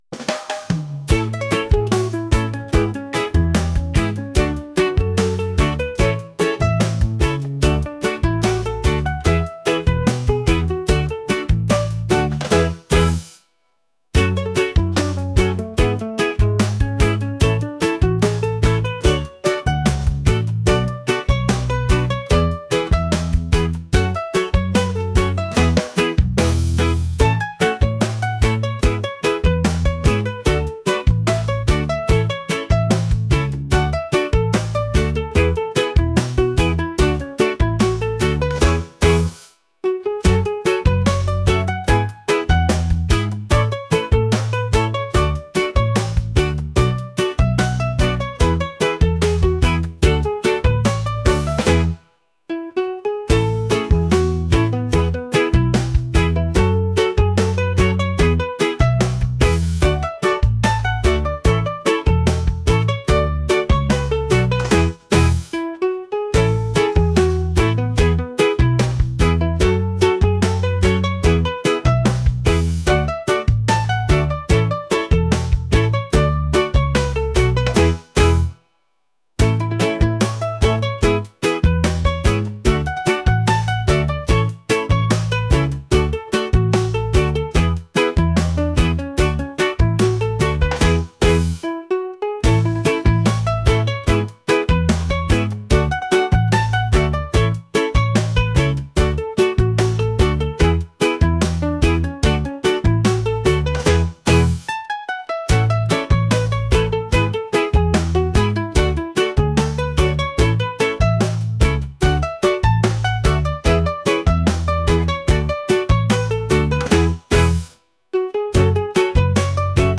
island | reggae